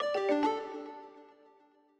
Longhorn Ten Beta - Notify Email.wav